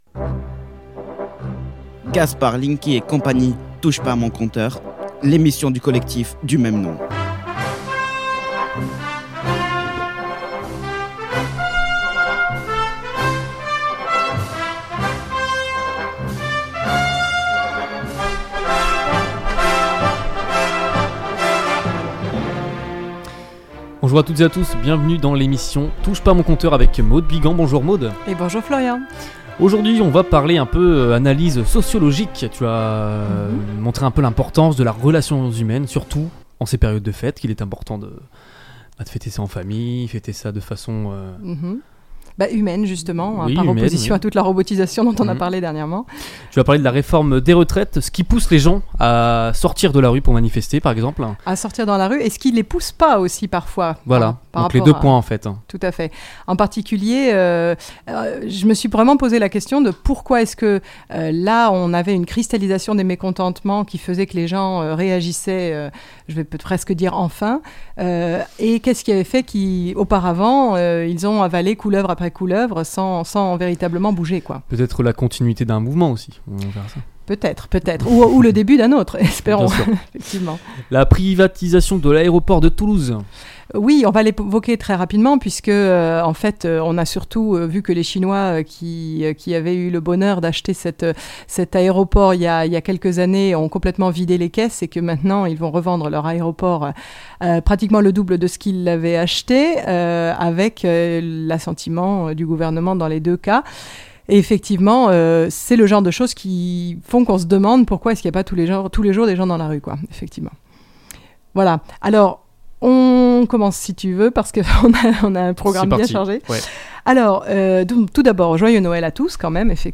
émission de radio transparence, le 26 Décembre 2019, Touche pas à mon compteur